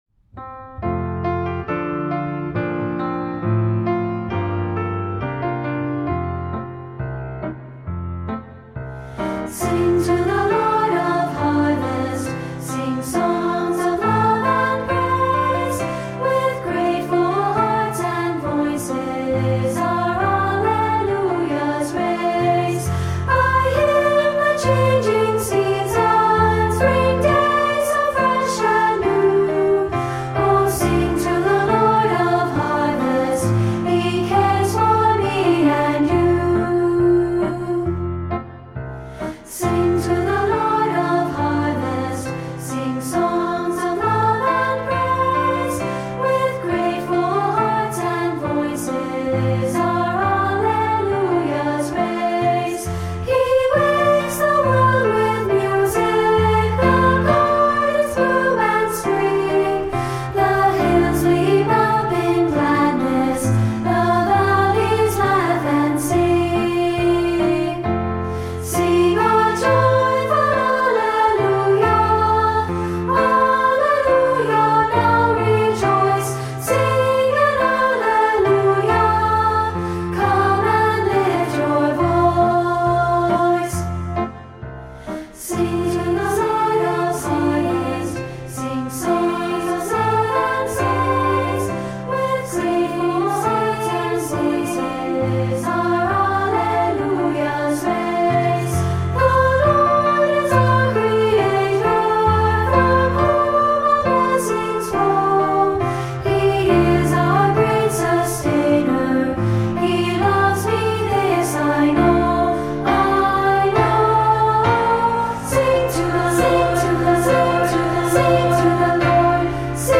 Voicing: Unison/2-Part and Piano